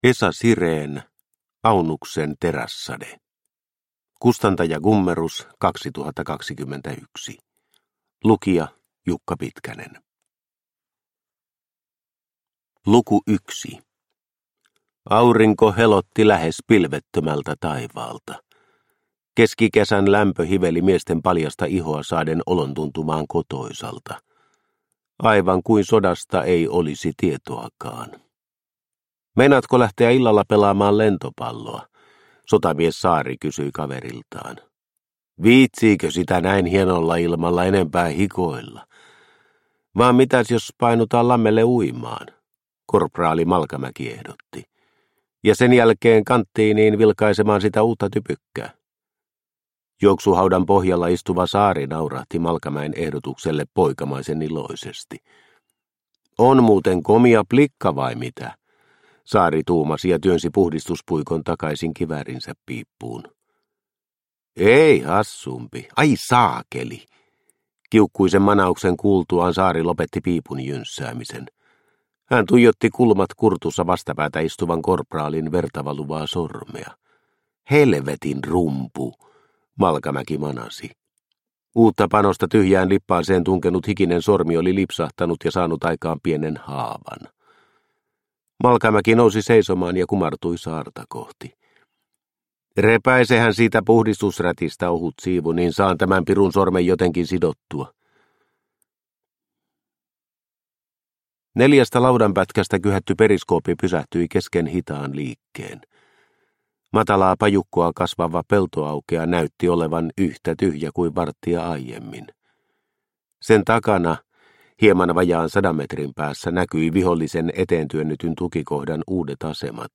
Aunuksen terässade – Ljudbok – Laddas ner